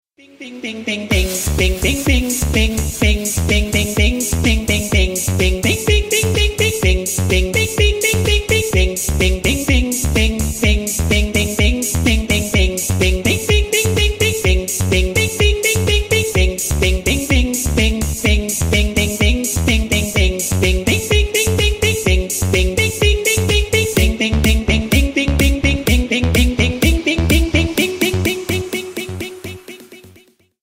Кавер И Пародийные Рингтоны